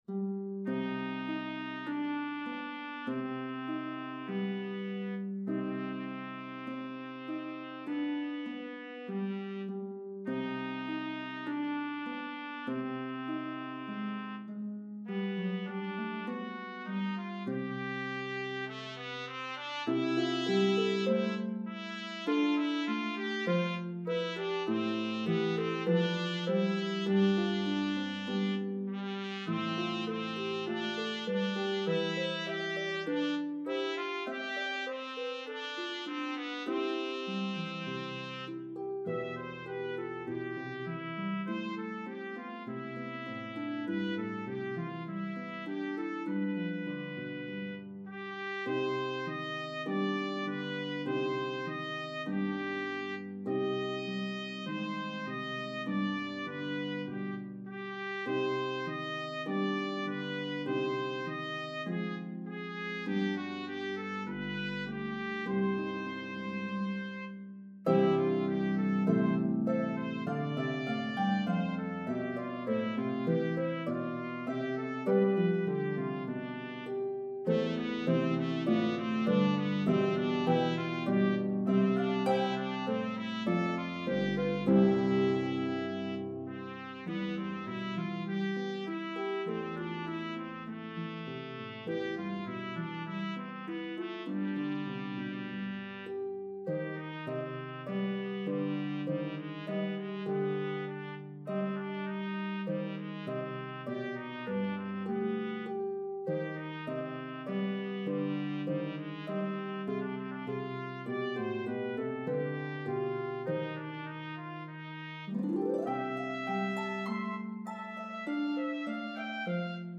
This cherished Carol recounts the birth of Christ.